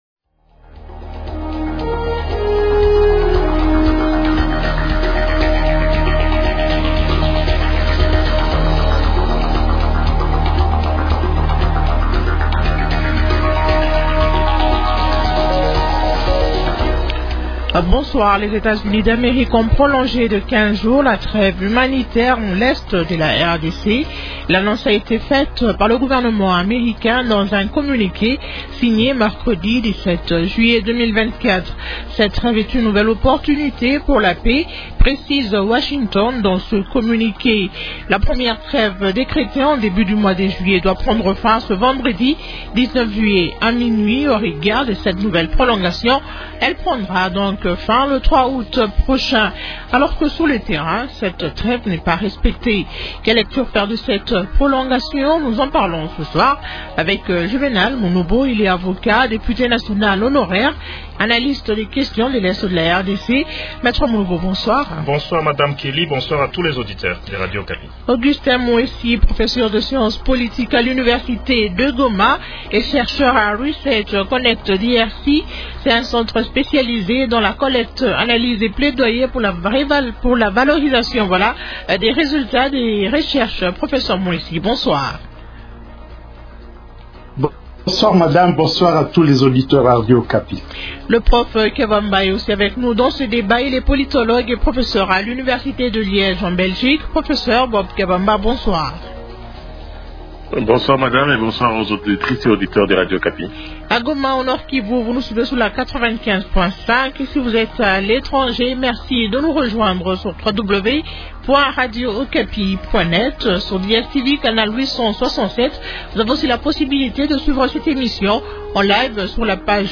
Question : -Quelle lecture faire de cette prolongation ? Invités : -Juvénal Munubo, avocat, il est député national honoraire et analyste des questions de l’Ets de la RDC.